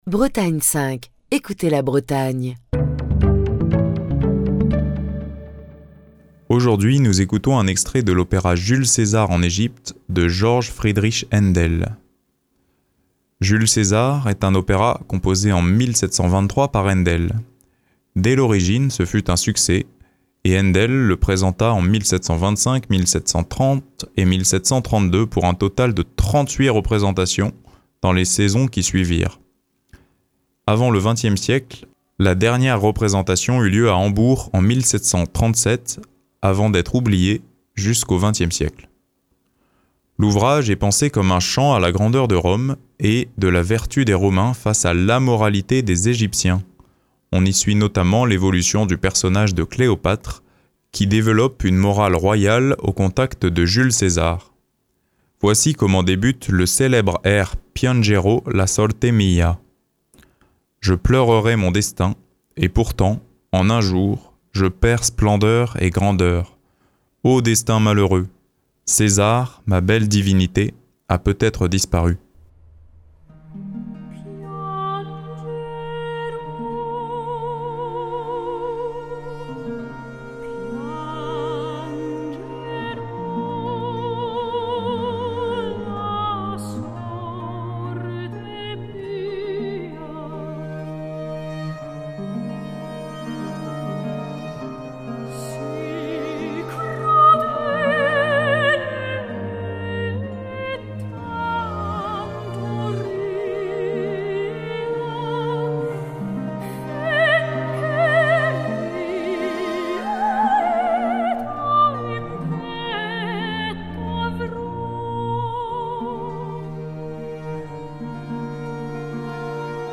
"Piangerò la sorte mia", un air poignant de l’opéra "Giulio Cesare in Egitto" de Haendel, est interprété par Sabine Devieilhe et l’Orchestre Pygmalion sous la direction de Raphaël Pichon.